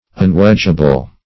Unwedgeable \Un*wedge"a*ble\, a. Not to be split with wedges.
unwedgeable.mp3